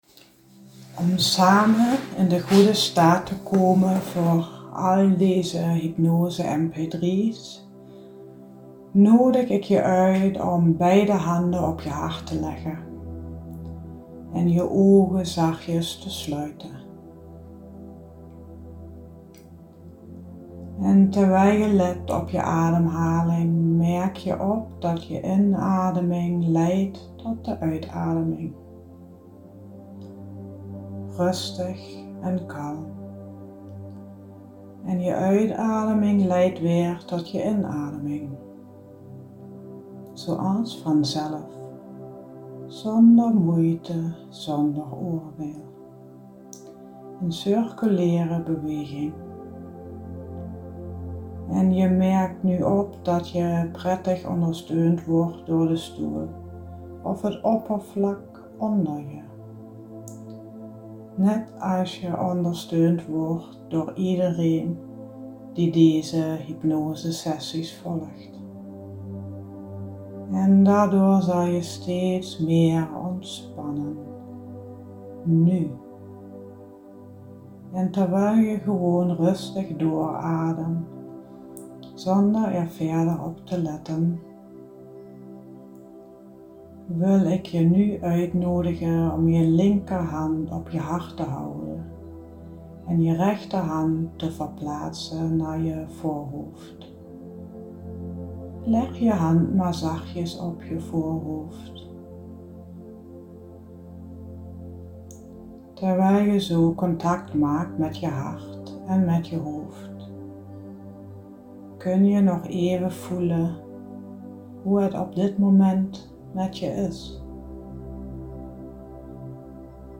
r kun je een hypnose MP3 beluisteren om een beetje in trance te raken.